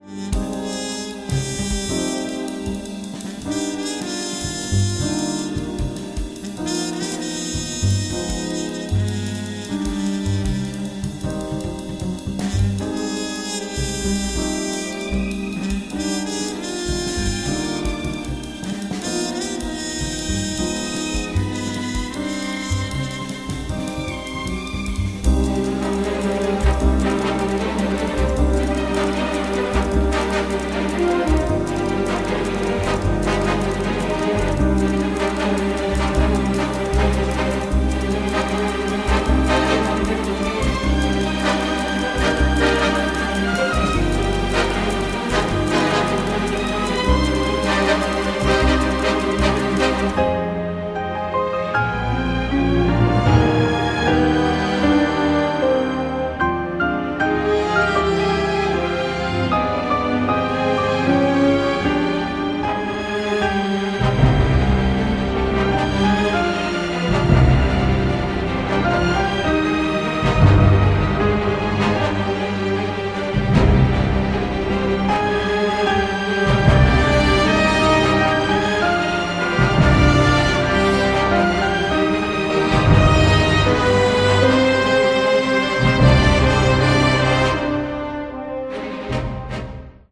propulsive orchestral score